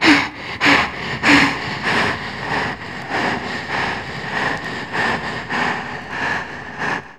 RUNVOX    -R.wav